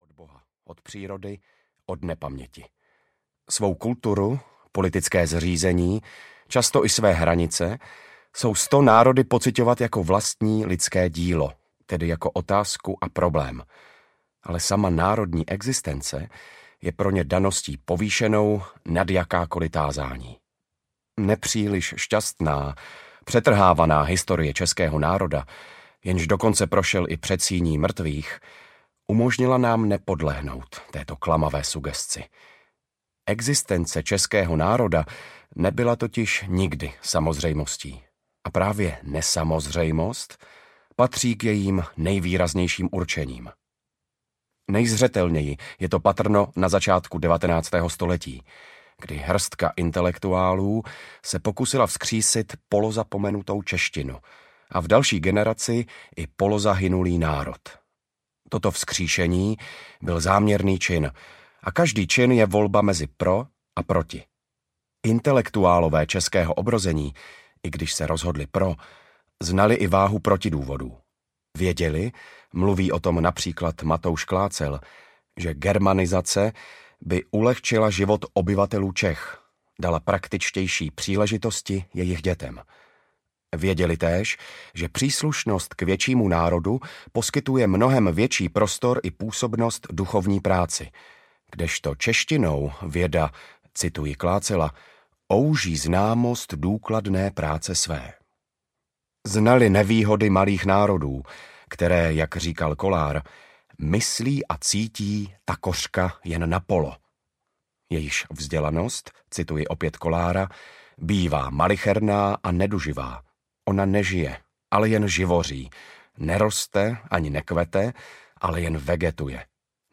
Unesený Západ audiokniha
Ukázka z knihy
V interpretaci Radúze Máchy se text stává výrazným a silným, velmi aktuálním sdělením.
• InterpretRadúz Mácha